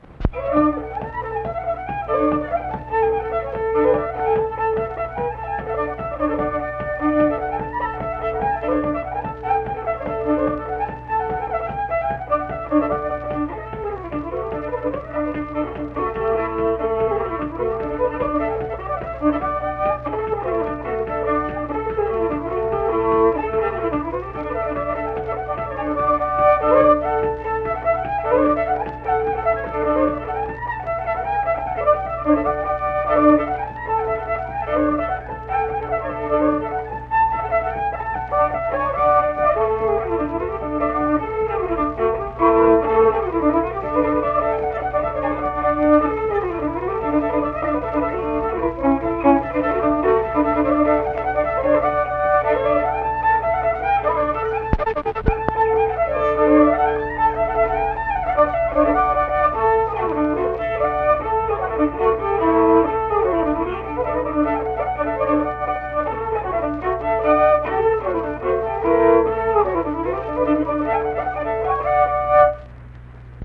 Unaccompanied fiddle music
Instrumental Music
Fiddle
Mingo County (W. Va.), Kirk (W. Va.)